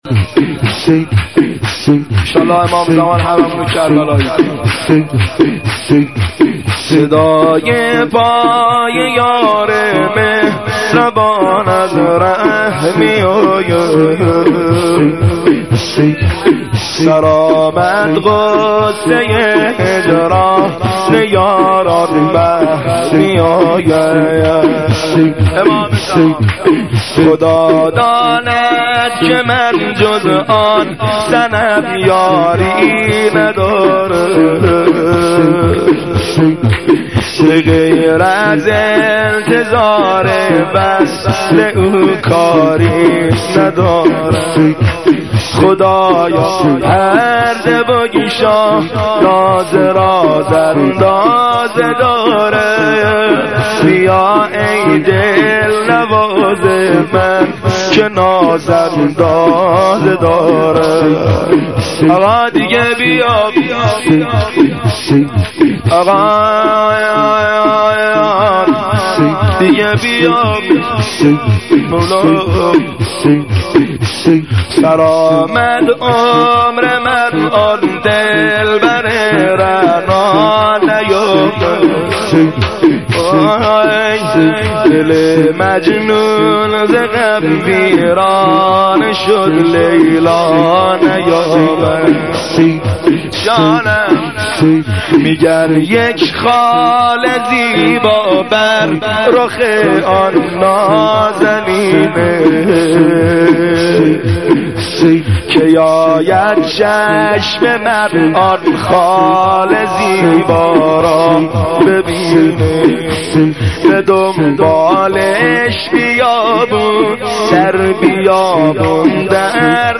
(شور - امام زمان عجل الله تعالی)